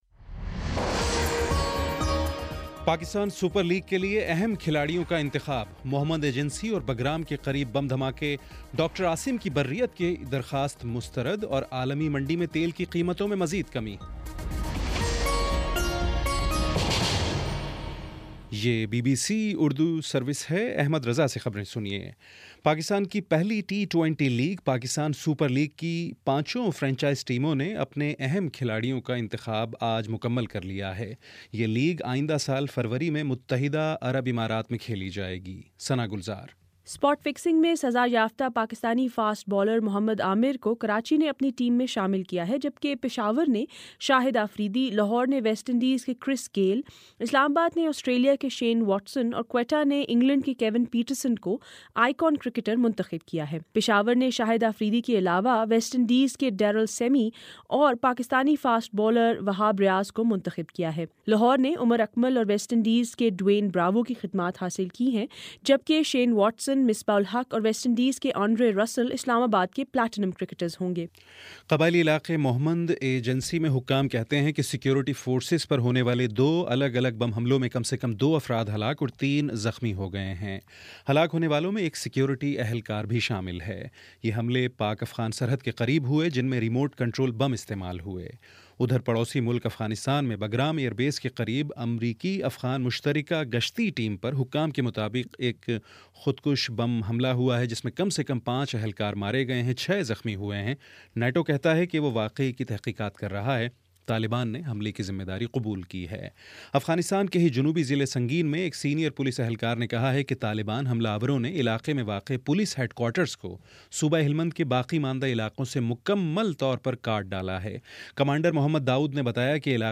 دسمبر 21 : شام چھ بجے کا نیوز بُلیٹن